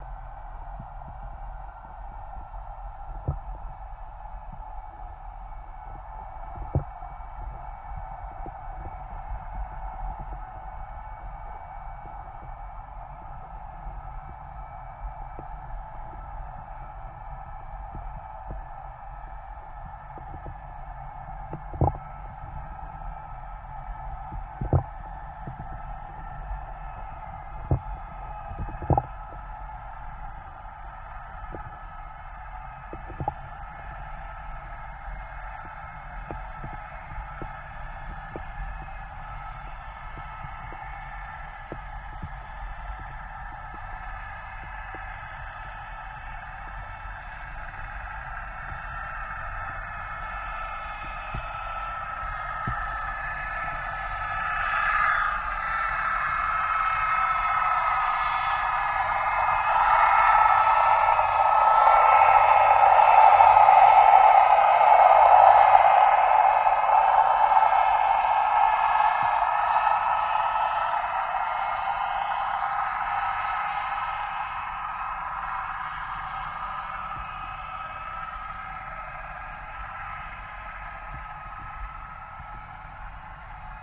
Torpedo Jet By